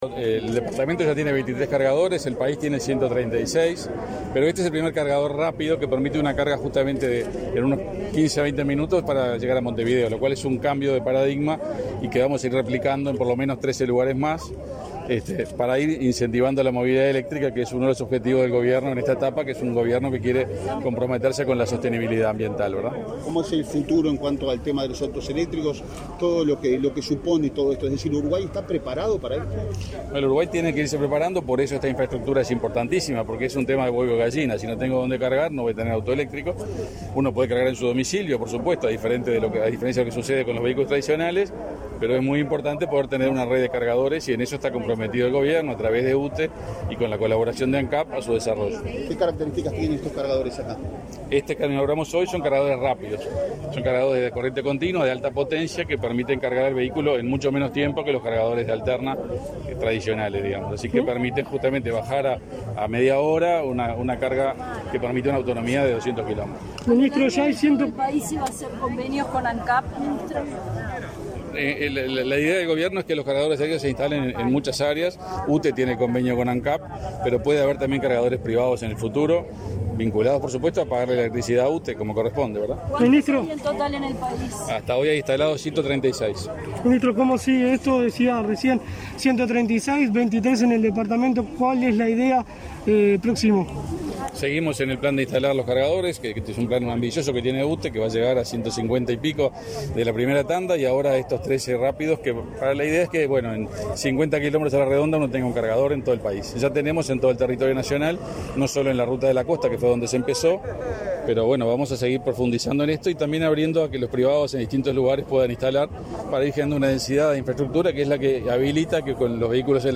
Declaraciones a la prensa del ministro de Industria, Energía y Minería , Omar Paganini
Tras participar en la inauguración de un punto de carga de UTE para vehículos eléctricos en la estación de Punta del Este, este 16 de febrero, el